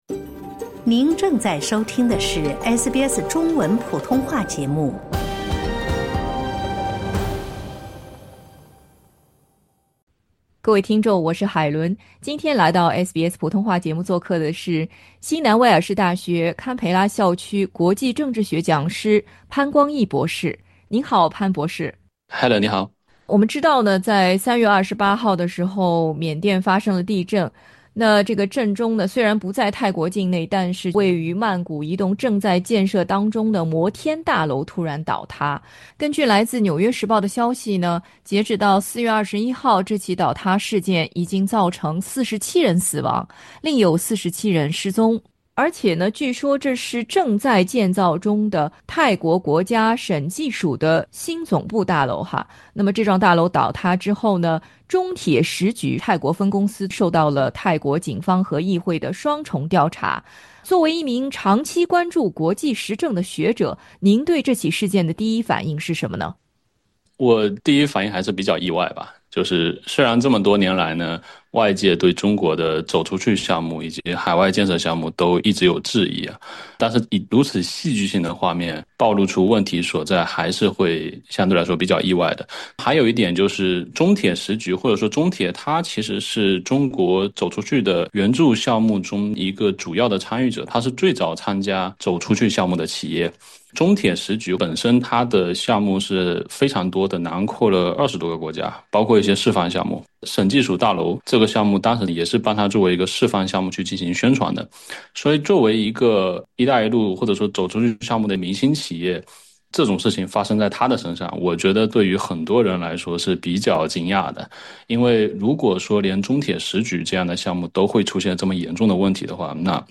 此次事件为国际合作项目敲响了警钟，如何建立更为有效的跨文化沟通机制、统一质量标准以及强化监管执行，已成为国际合作项目中亟待解决的重要课题。 （采访内容仅为专家观点，不代表本台立场） 欢迎下载应用程序SBS Audio，关注Mandarin。